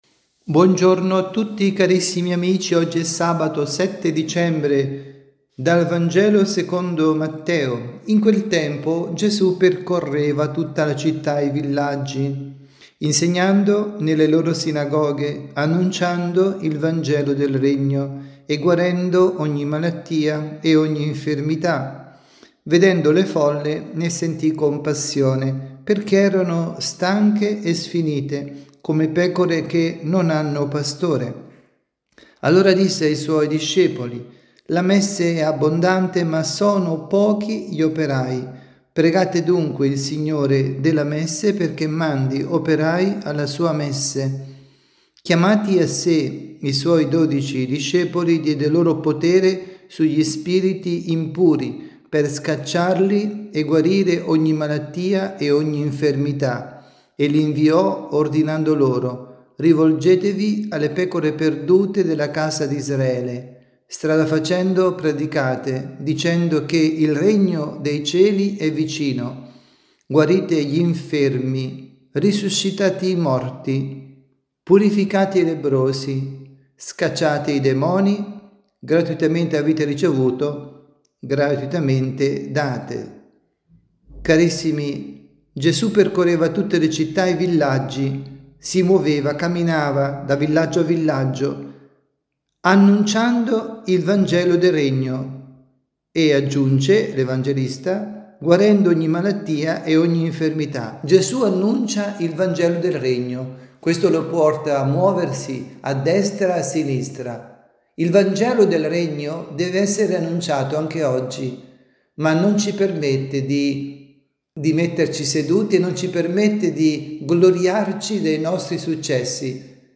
Avvento, avvisi, Catechesi, Omelie
dalla Basilica di San Nicola – Tolentino